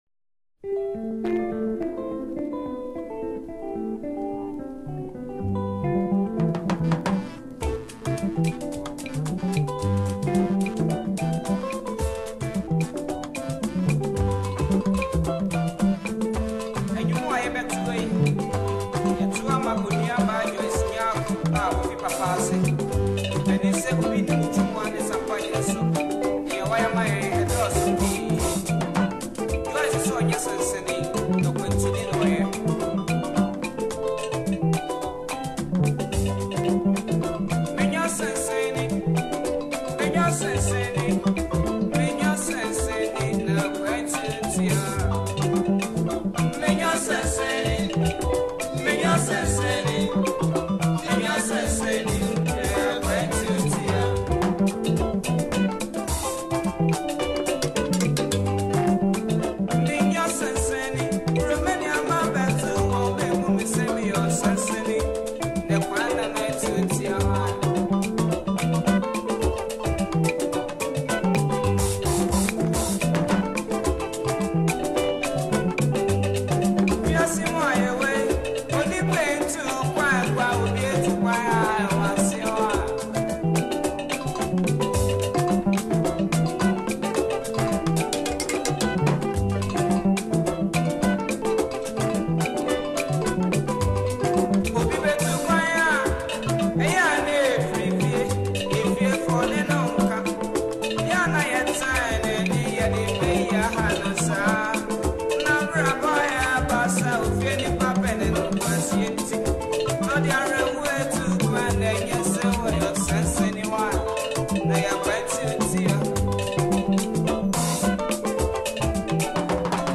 an ancient highlife song